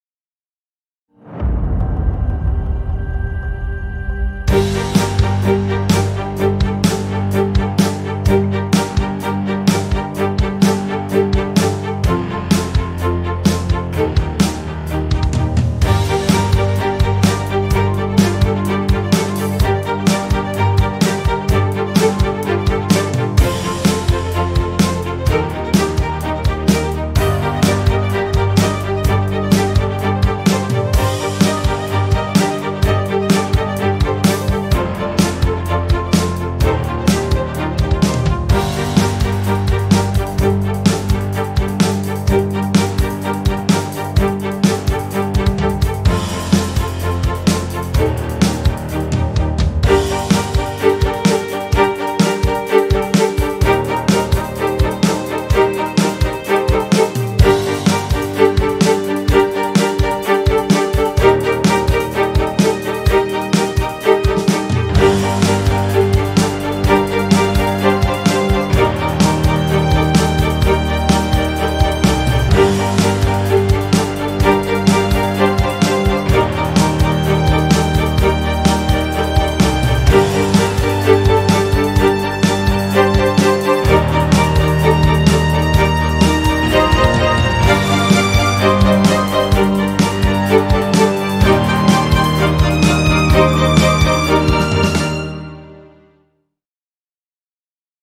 tema dizi müziği, duygusal heyecan aksiyon fon müziği.